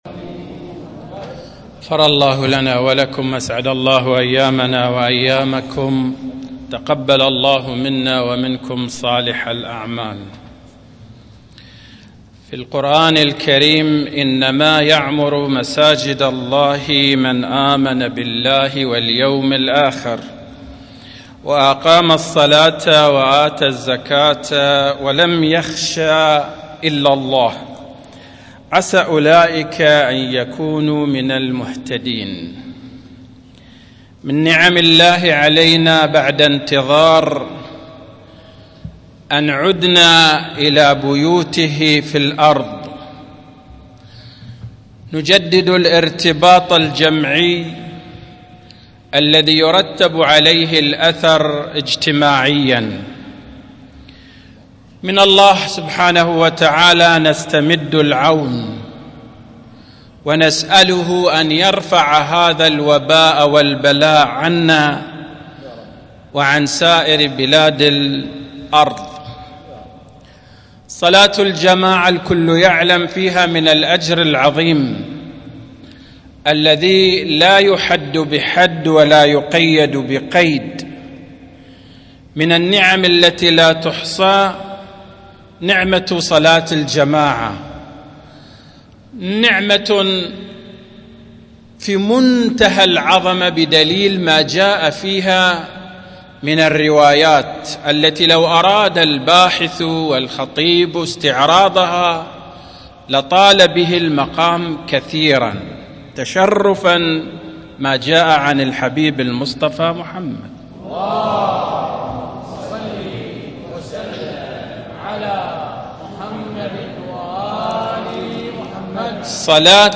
القسم : محاضرات يوم الجمعه بجامع الإمام الحسين عليه السلام